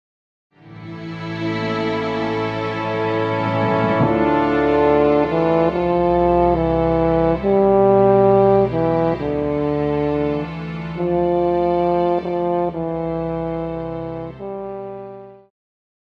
Pop
French Horn
Band
Instrumental
Only backing